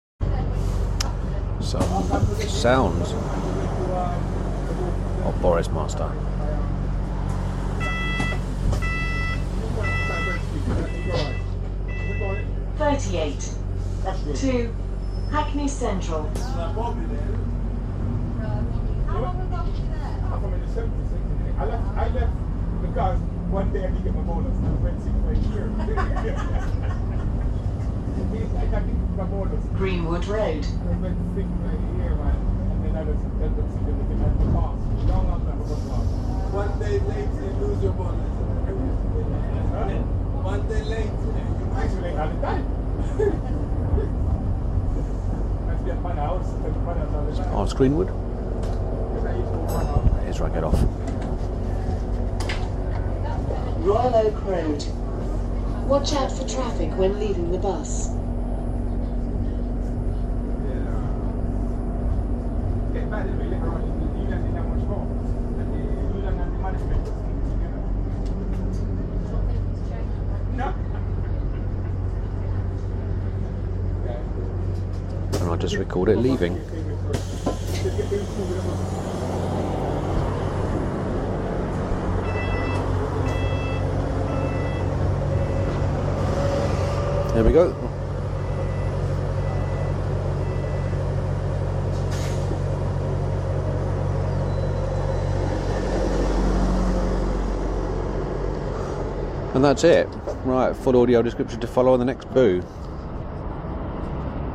In which I record the sound of the New Bus for London - inside and out.